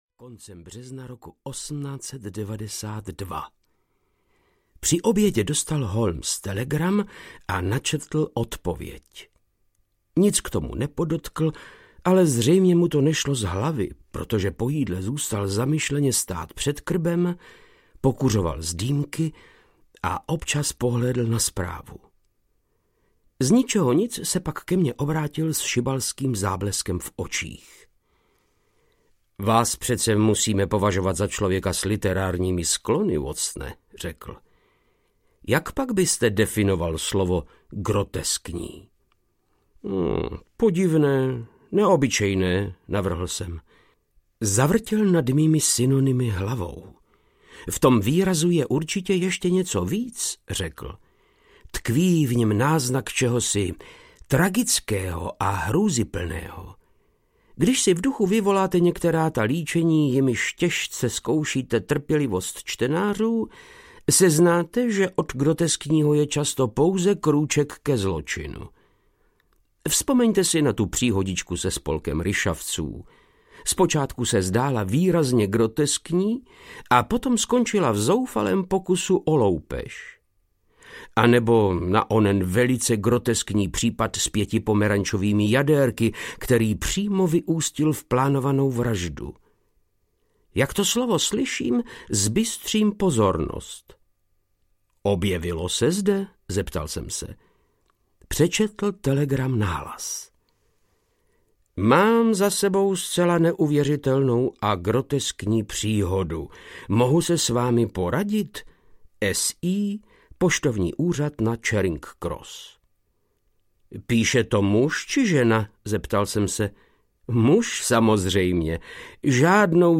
Poslední poklona Sherlocka Holmese audiokniha
Ukázka z knihy
• InterpretVáclav Knop